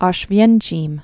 (ôsh-vyĕnchēm) Formerly Ausch·witz (oushvĭts)